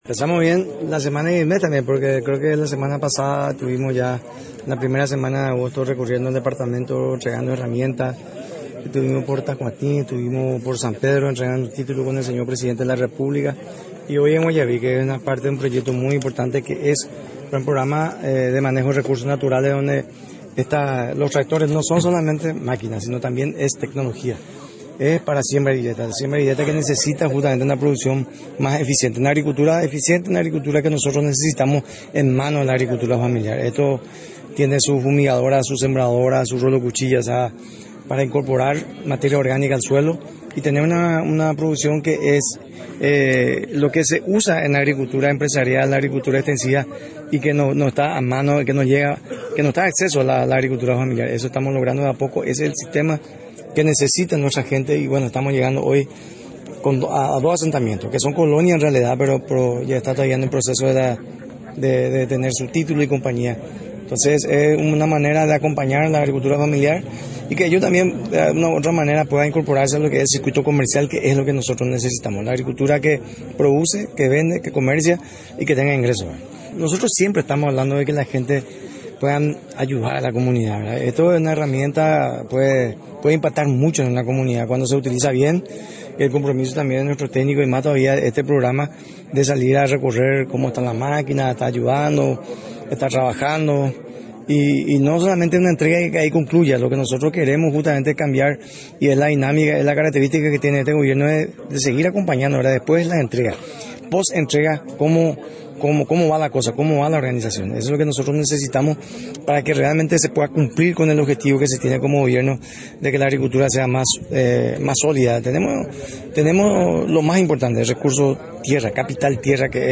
En la ocasión, el Dr. Vet. Carlos Giménez, resaltó el rol fundamental de la Agricultura Familiar y aseguró que el gobierno acompaña al sector productivo.